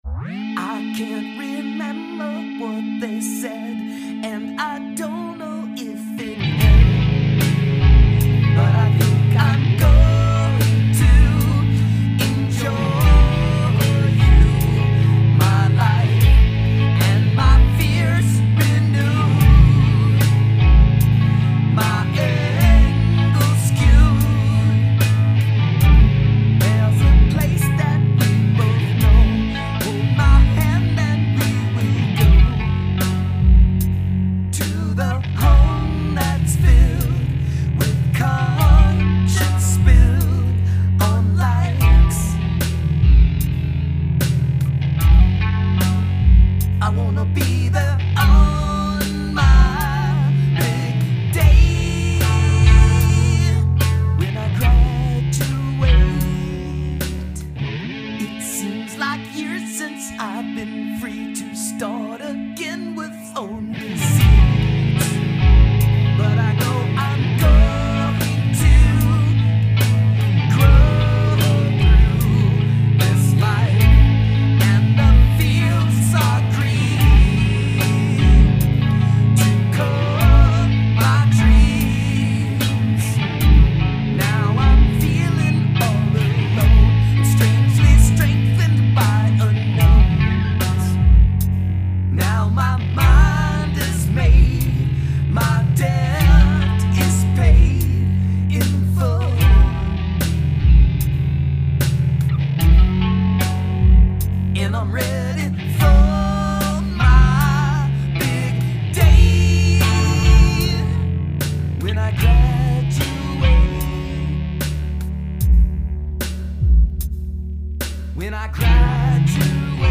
People called us "math-rock"; people sort of liked us.